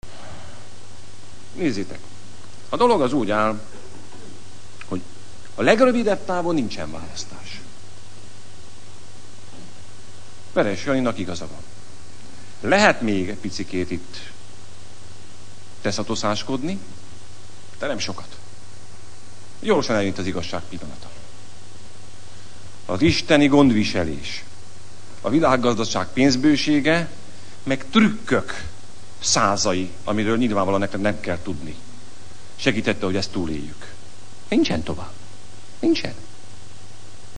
A miniszterelnök őszödi beszédének szöveghű részletei: